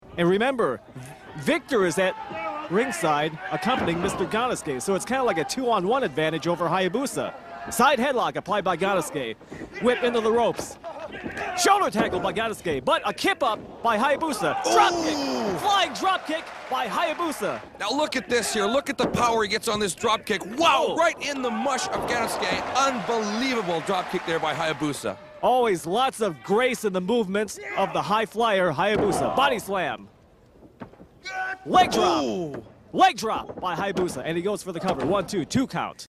embarrassing dubbed
FMW-English-commentary.mp3